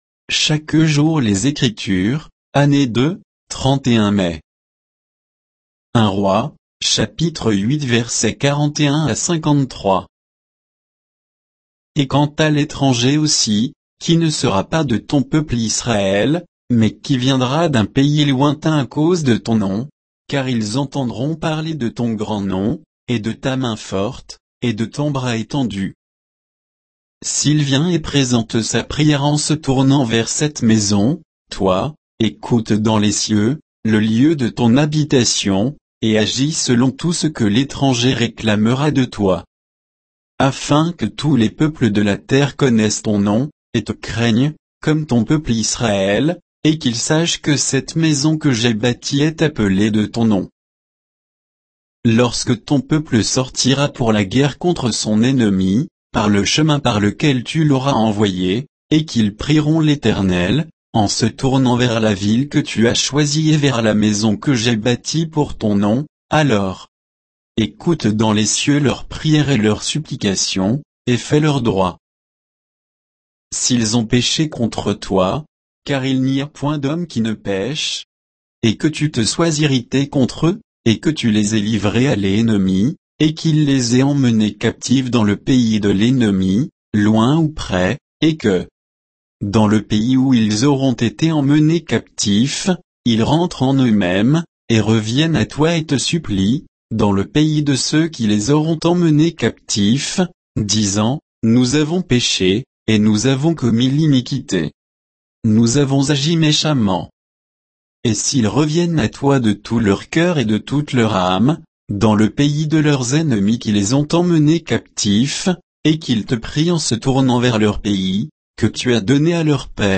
Méditation quoditienne de Chaque jour les Écritures sur 1 Rois 8